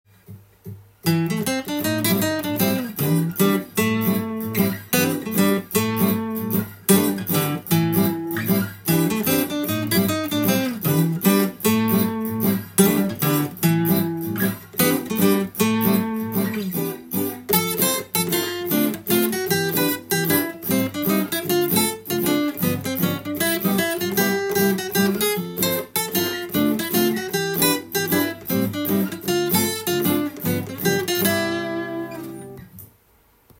譜面通り弾いてみました
まさにモダンジャズの名曲という感じです。
拍子が５拍子になるので少しリズムがとりにくくなりますが